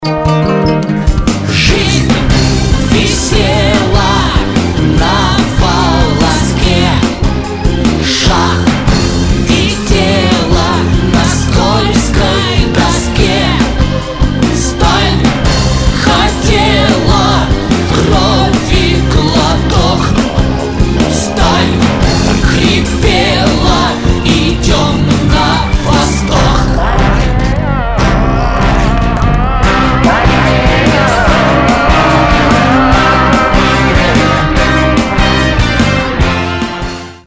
• Качество: 192, Stereo
брутальные